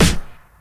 • 00s Rap Snare One Shot G# Key 17.wav
Royality free snare drum sound tuned to the G# note. Loudest frequency: 1764Hz
00s-rap-snare-one-shot-g-sharp-key-17-SUA.wav